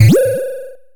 Laser_03.mp3